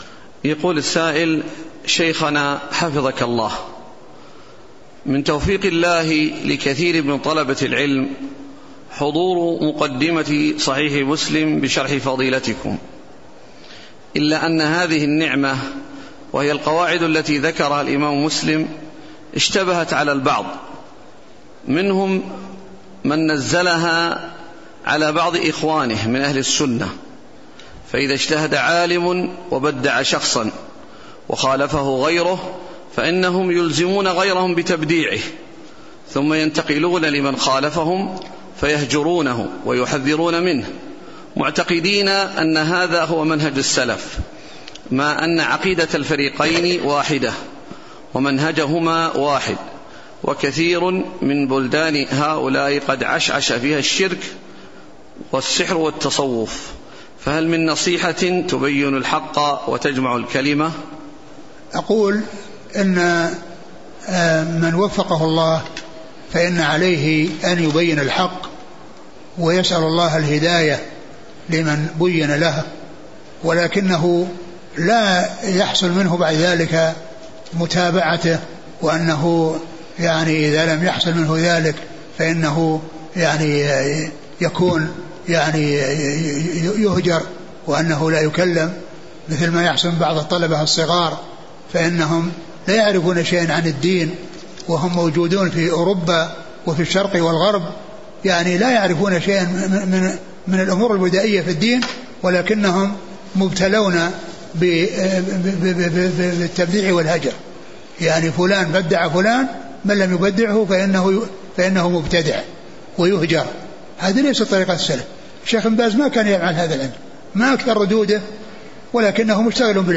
Asked on 8/12/2013 in the Prophet’s Mosque.